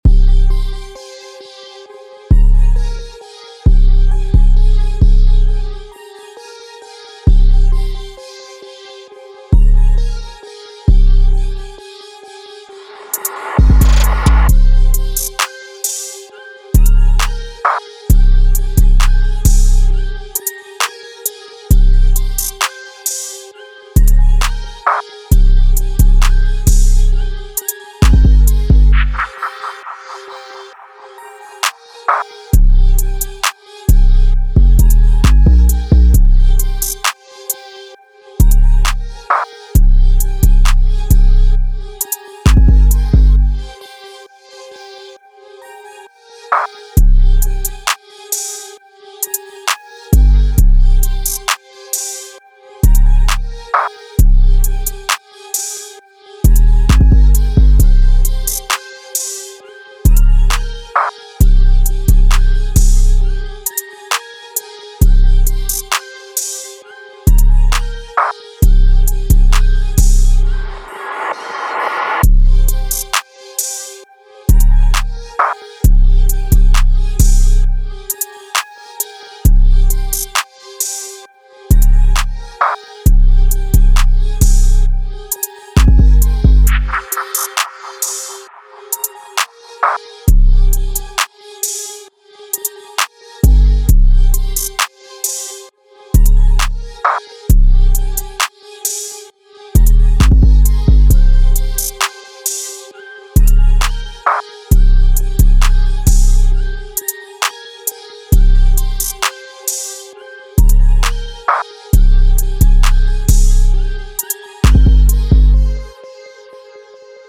Trap Beats